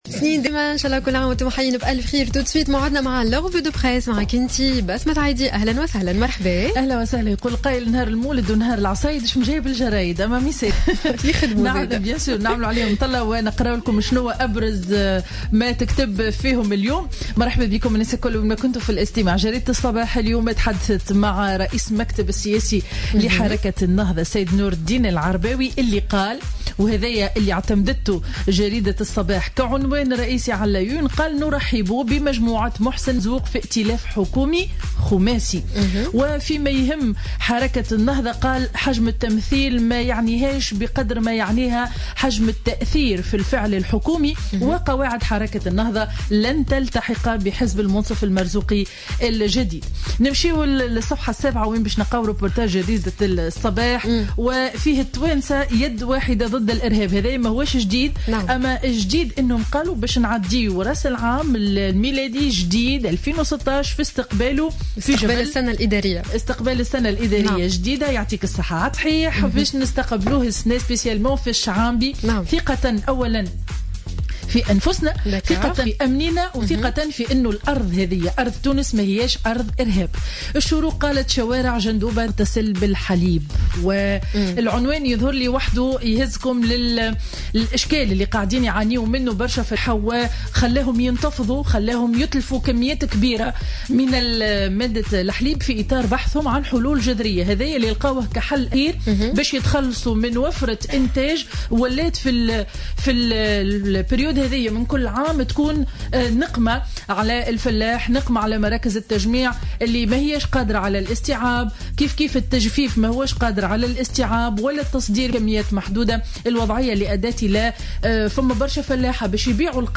Revue de presse du jeudi 24 décembre 2015